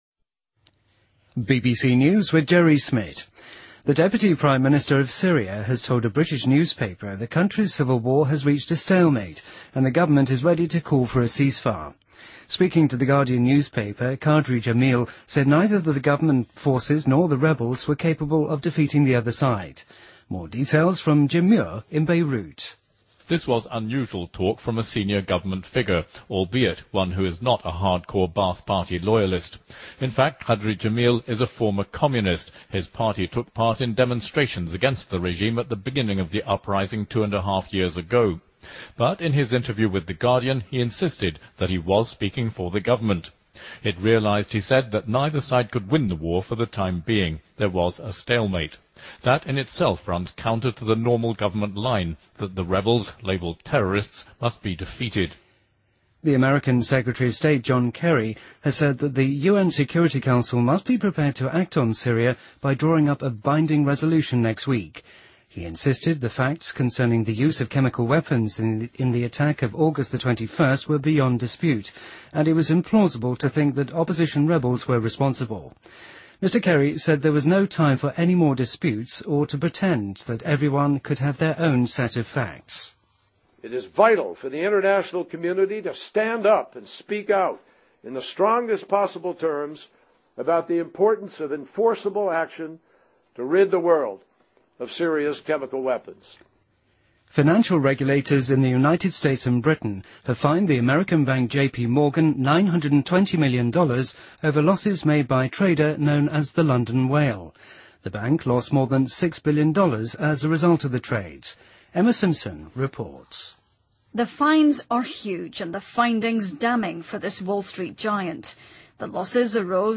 BBC news,德克萨斯法院推翻了对汤姆·迪莱的定罪